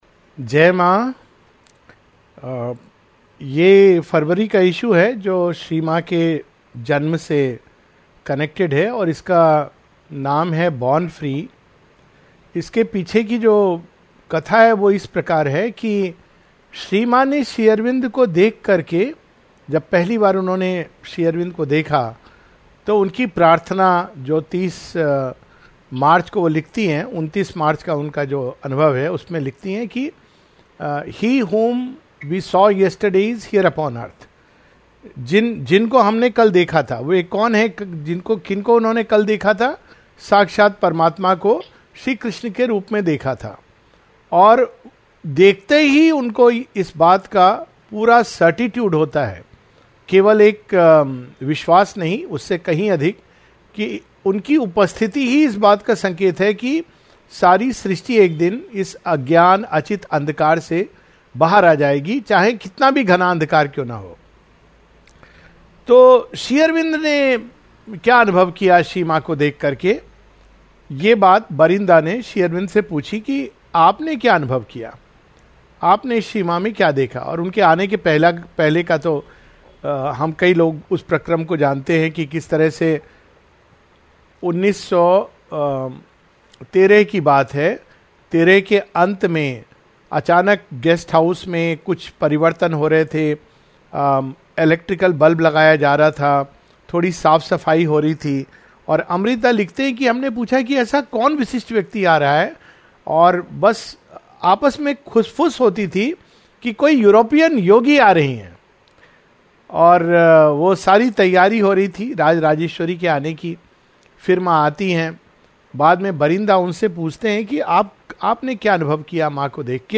A few glimpses of the Mother's life, based on Feb. 2025 issue of the All-India Magazine. A talk